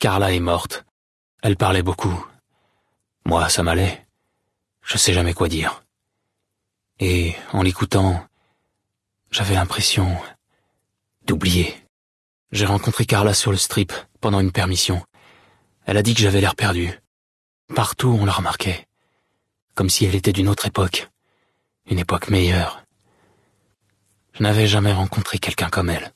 Dialogue audio de Fallout: New Vegas